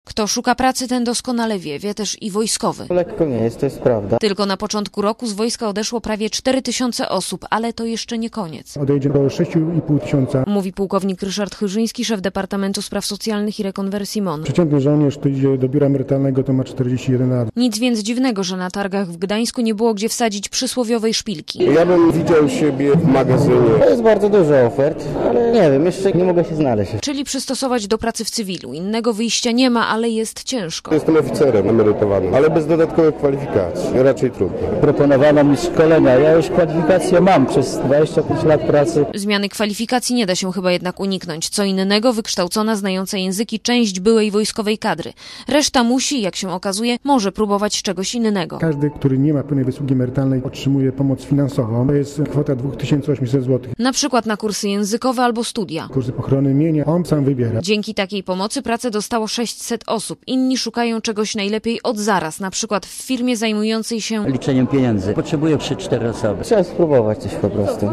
Posłuchaj relacji reporterki Radia Zet(530 KB)